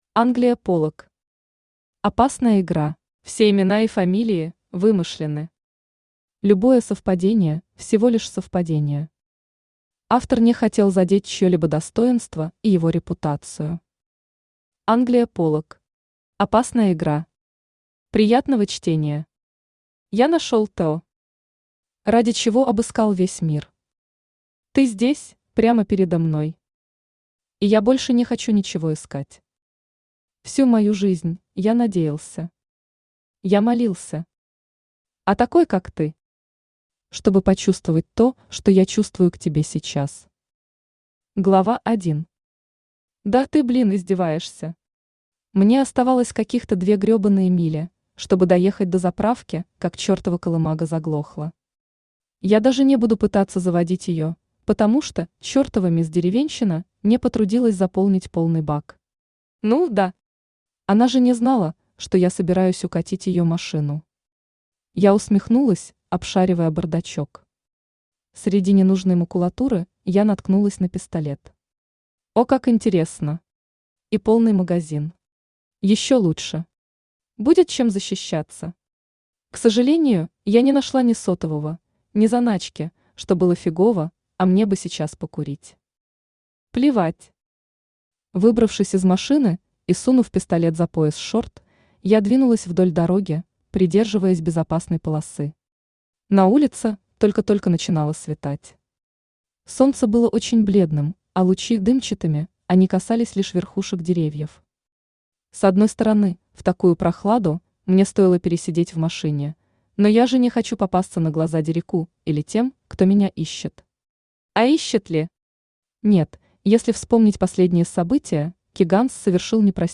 Аудиокнига Опасная Игра | Библиотека аудиокниг
Aудиокнига Опасная Игра Автор Англия Полак Читает аудиокнигу Авточтец ЛитРес.